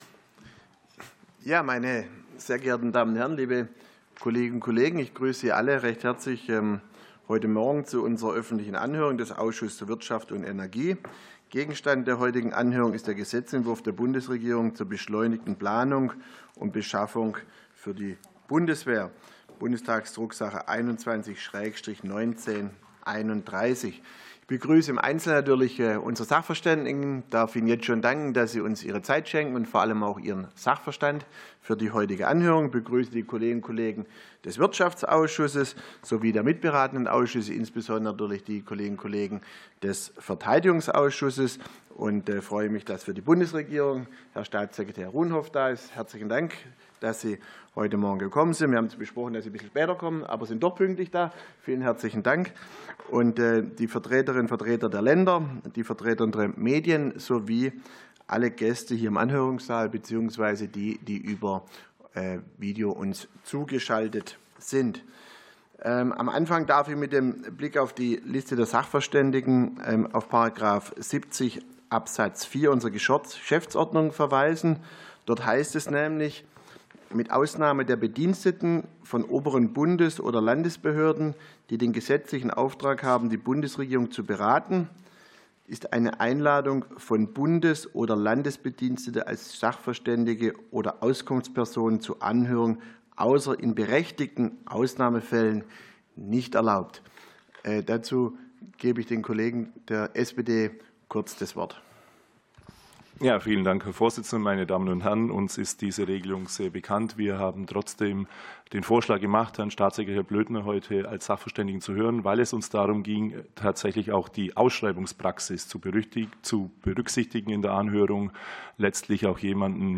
Anhörung des Ausschusses für Wirtschaft und Energie